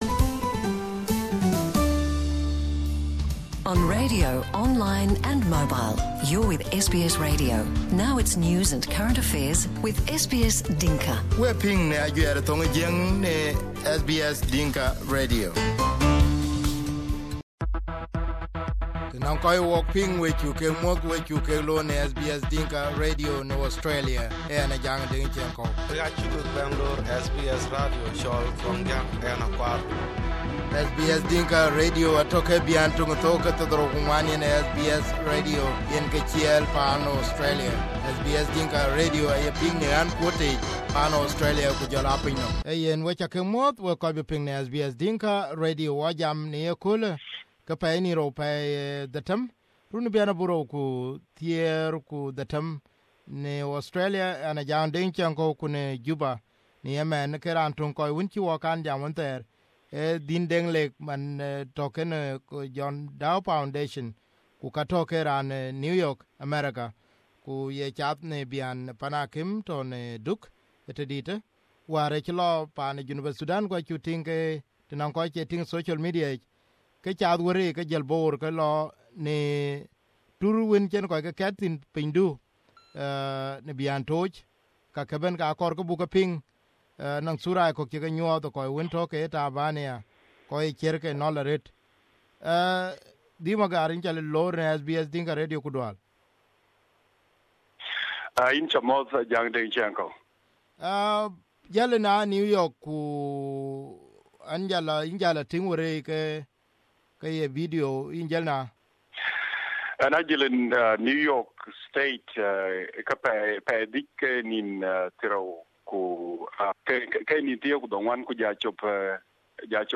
In his first interview on SBS Dinka Radio